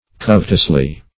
Covetously \Cov"et*ous*ly\, adv.